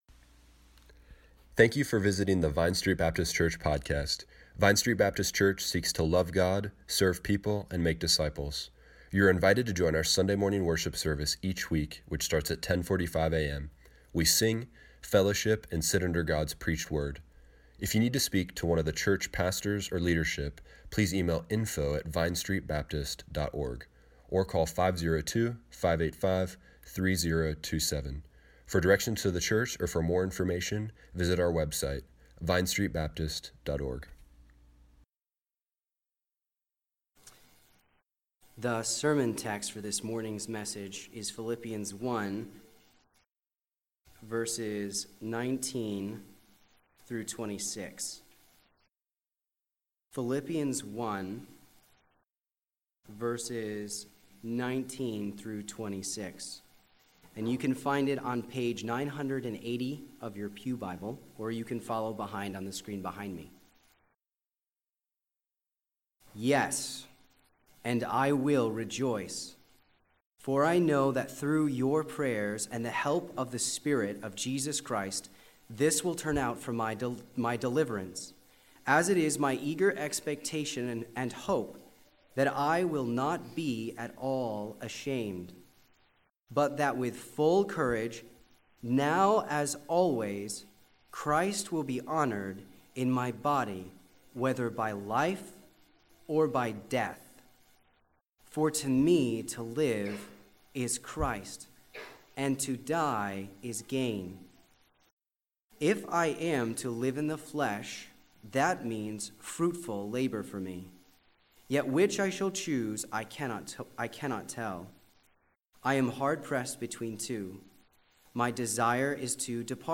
A Study in Unity Service Morning Worship Tweet Summary February 24, 3019 Application: Find our joy in the salvation we are guaranteed if we are believers in Christ. Value Christ above all things Find joy that death is gain Live in a way that your life is fruitful labor for the Lord Click here to listen to the sermon online.